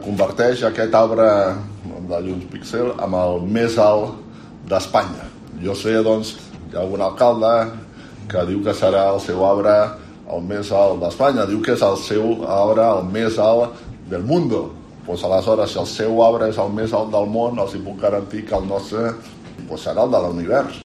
Xavier Garcia Albiol, Alcalde de Badalona